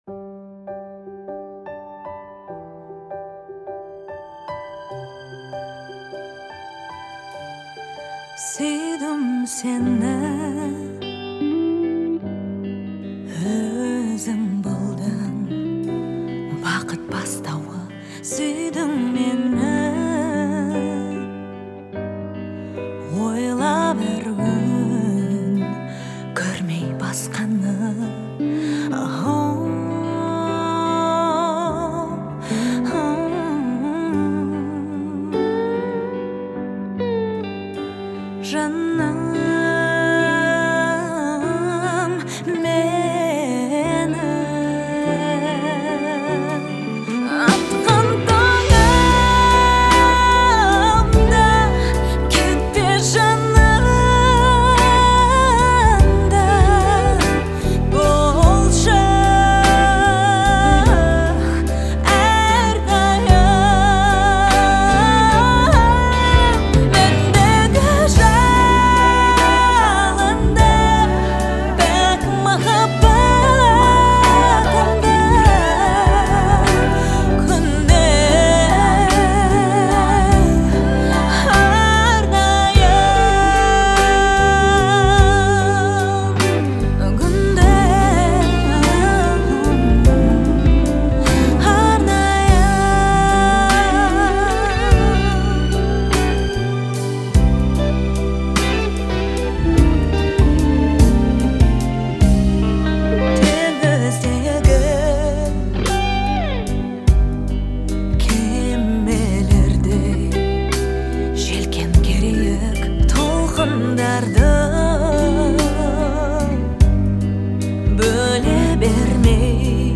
это мелодичный поп-трек